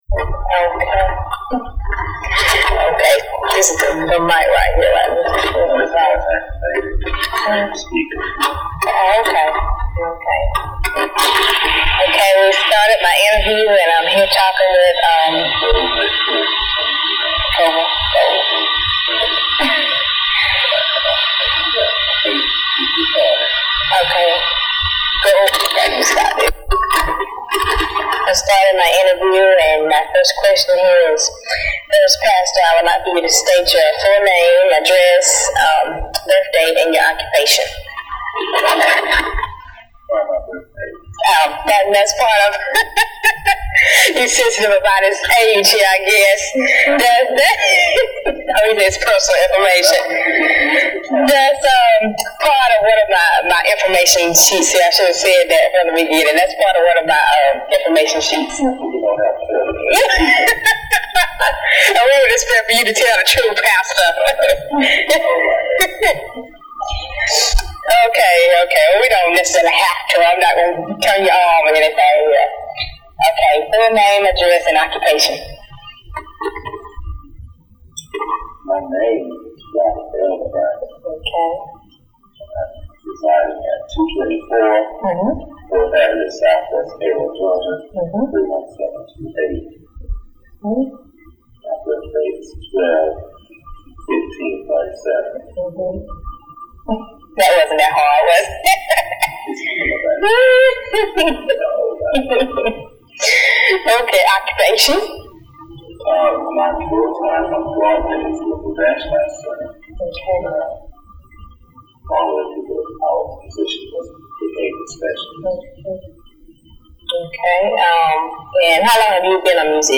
Part of the South Georgia Folklife Project at Valdosta State University Archives and Special Collections. Note: Poor audio quality.